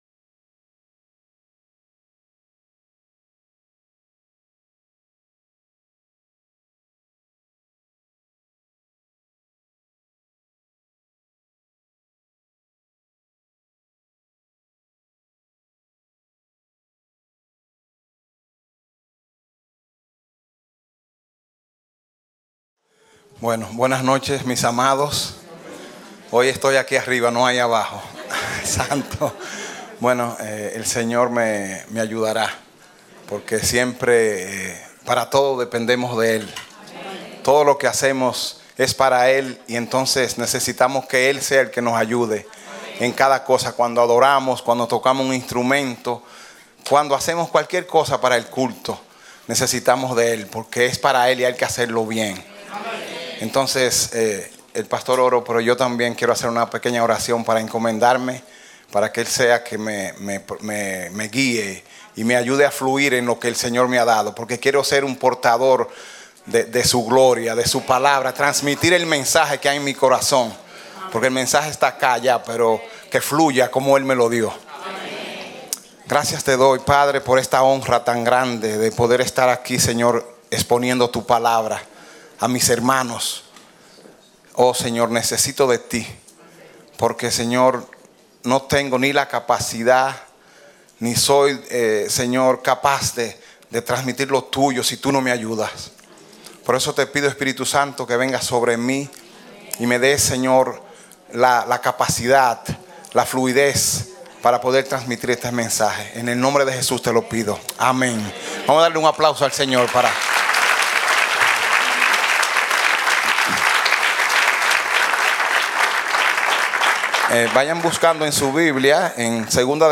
Predicado Jueves 14 de Enero, 2016